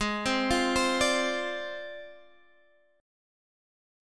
Ab6b5 chord